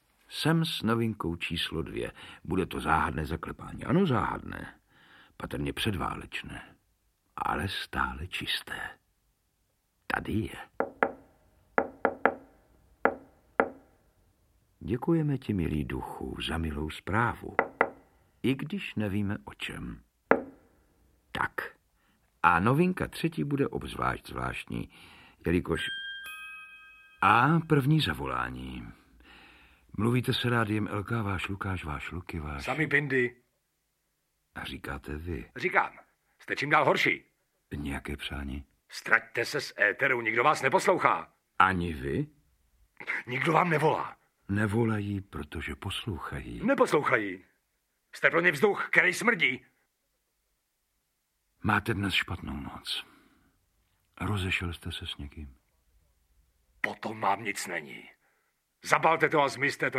Audiobook
Audiobooks » Short Stories
Read: David Novotný